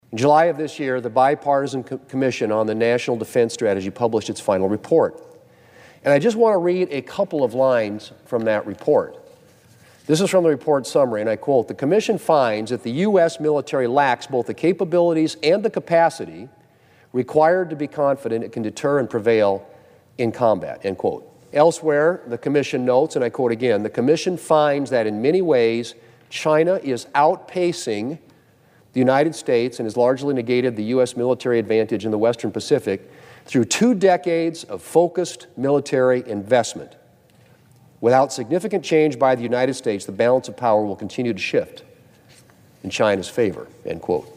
WASHINGTON, D.C.(HubCityRadio)- Earlier this week, Senator John Thune was on the floor of the Senate stressing the importance of getting the National Defense Authorization Act(NDAA) done by the end of this year.